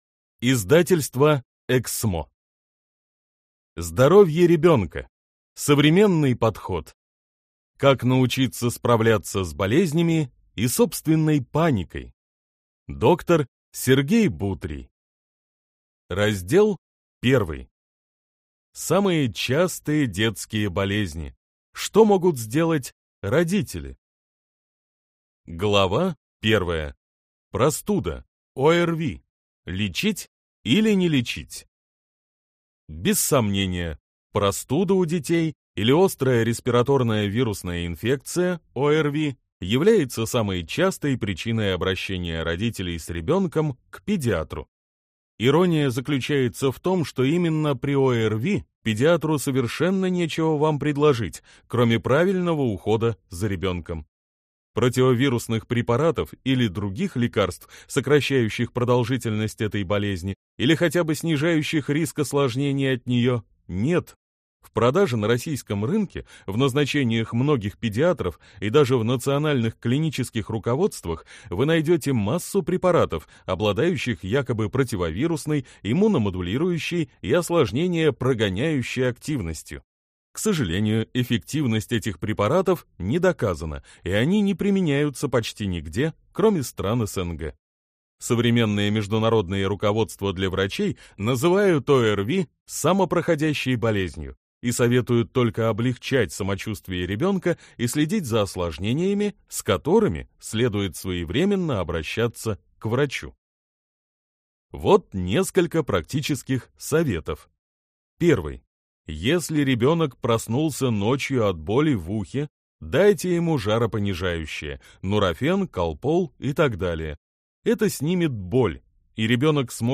Аудиокнига Здоровье ребенка: современный подход. Как научиться справляться с болезнями и собственной паникой | Библиотека аудиокниг